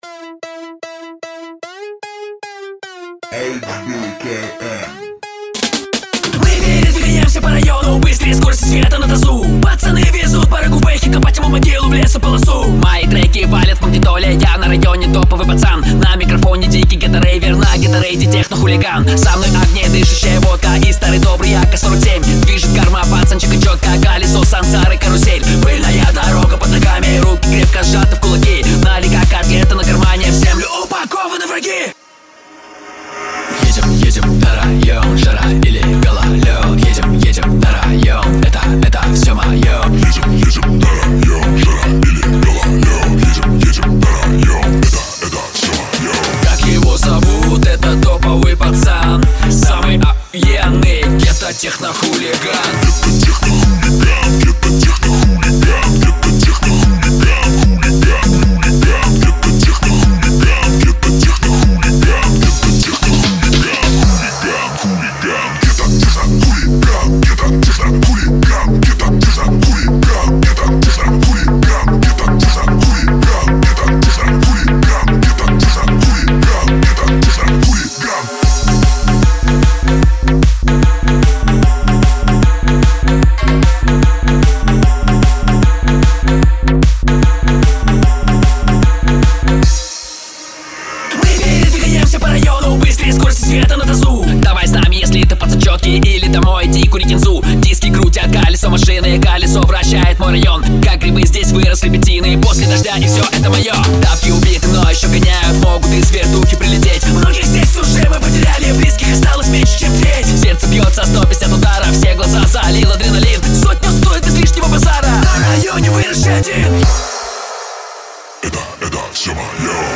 ghettorave
hardbass